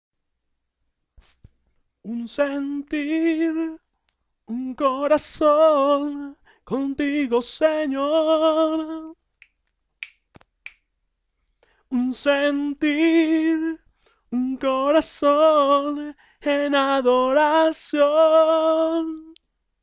1a Voz Precoro Hombre